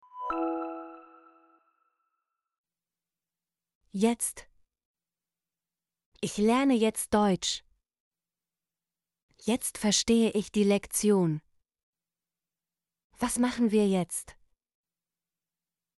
jetzt - Example Sentences & Pronunciation, German Frequency List